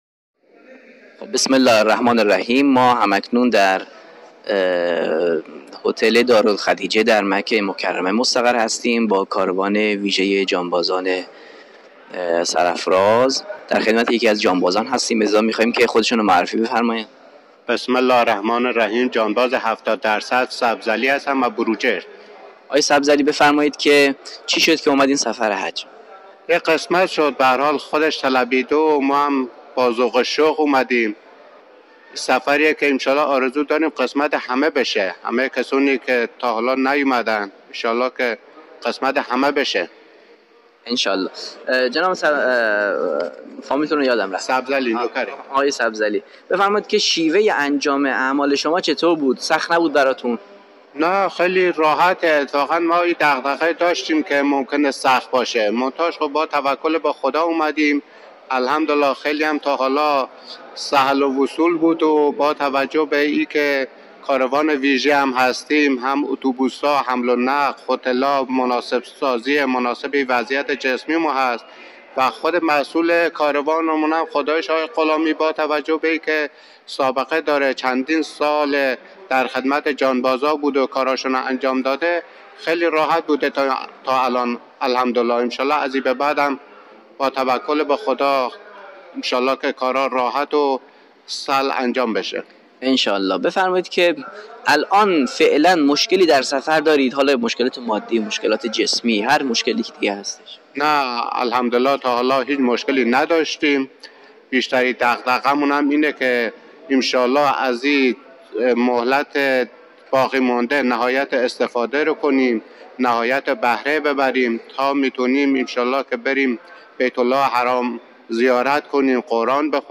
جانباز 70 درصد: